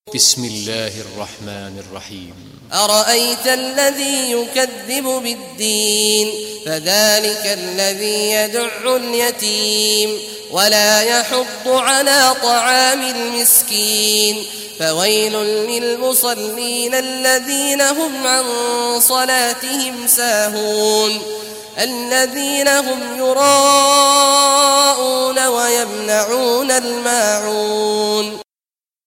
Surah Maun Recitation by Sheikh Awad Juhany
Surah Maun, listen or play online mp3 tilawat / recitation in Arabic in the beautiful voice of Imam Sheikh Abdullah Awad Al Juhany.